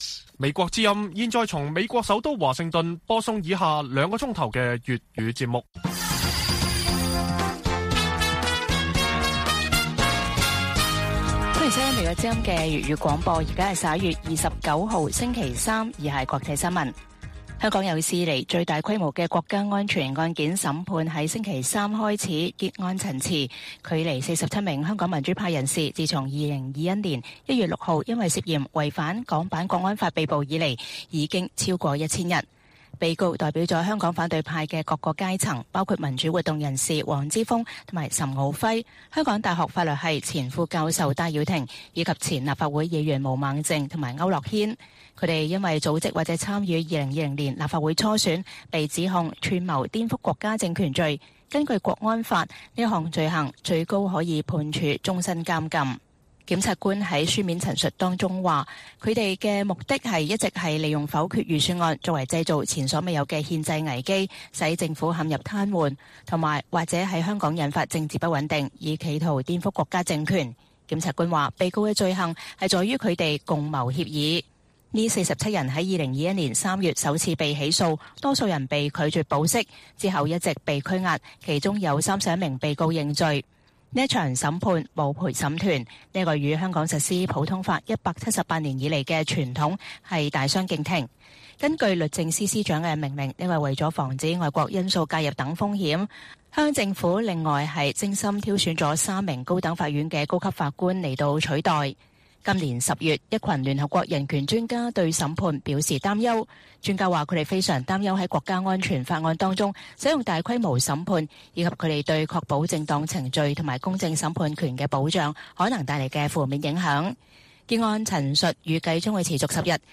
粵語新聞 晚上9-10點: 香港國安法最大宗案件開始結案陳詞*